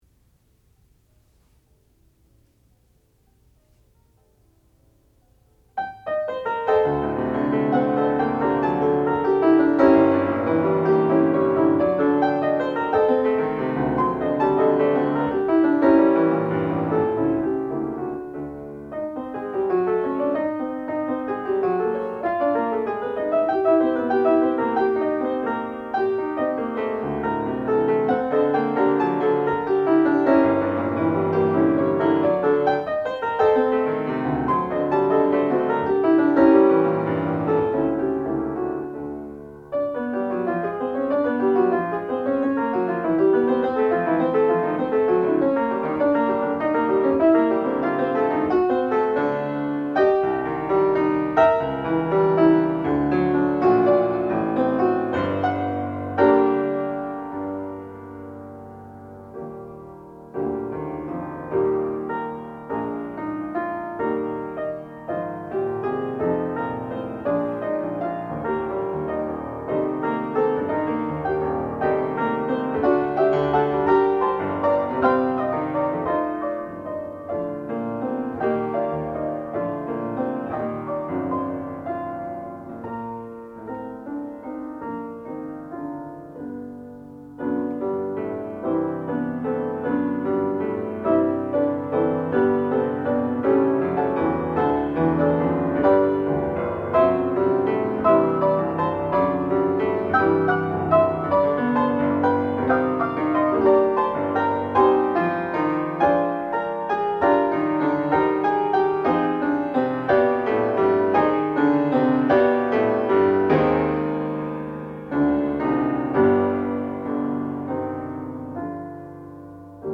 sound recording-musical
classical music
Advanced Recital
piano